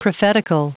Prononciation du mot prophetical en anglais (fichier audio)
Prononciation du mot : prophetical